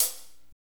HAT F S C0GL.wav